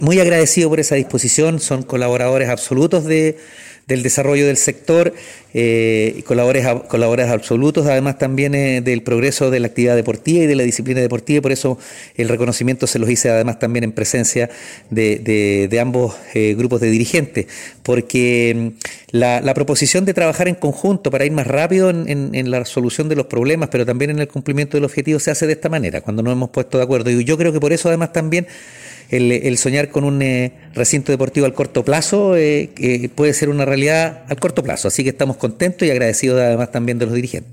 Alcalde-Oscar-Calderon-Reunion-con-clubes-Manuela-Figueroa-y-Rayon-Said-2.mp3